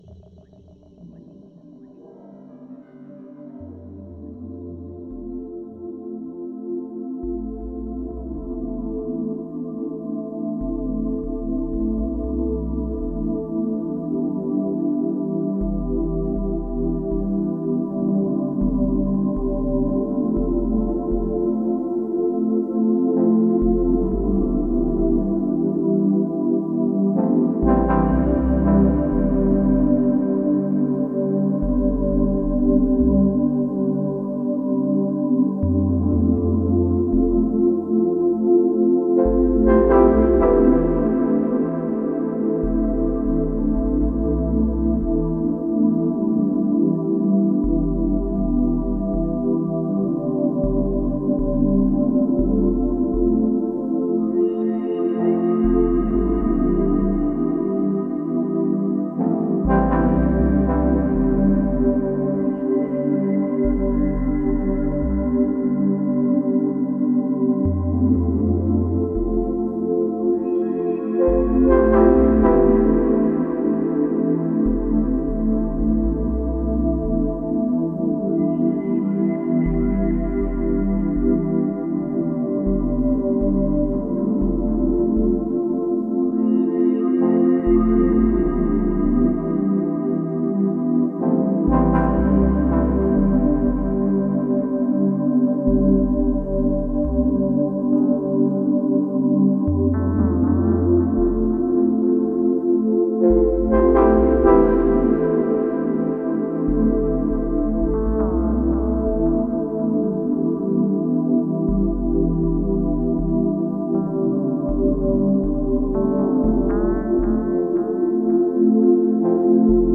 Genre: Deep Ambient.